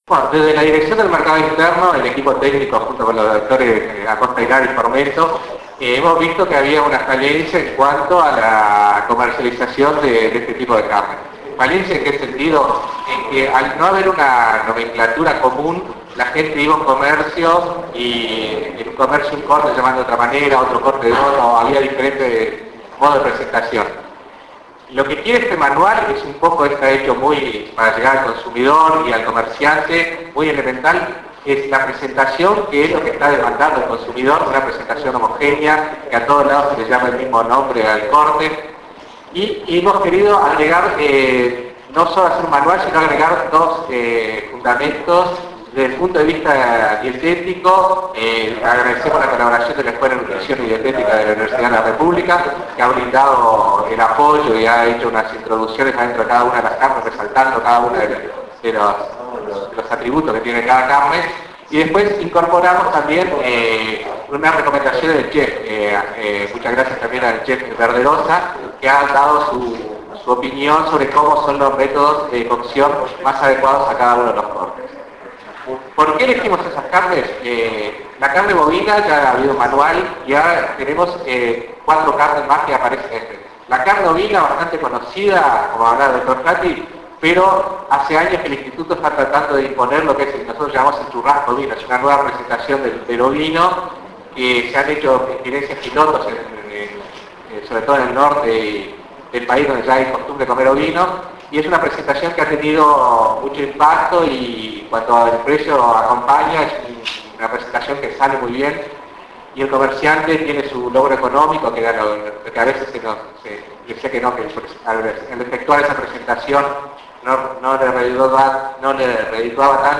Mercado Interno  Presentación de Manual de Carnes Alternativas  5:02